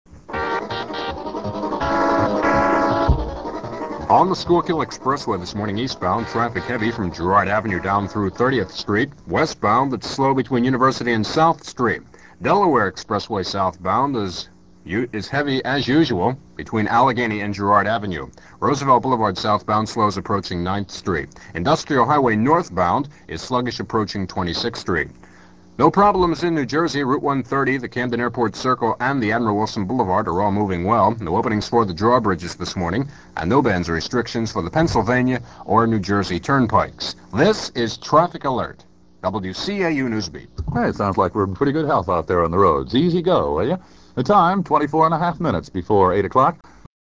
The following clips are from an October 15, 1970 aircheck of WCAU-AM's Morning NewsBeat program, which was all news from 6 to 9 a.m.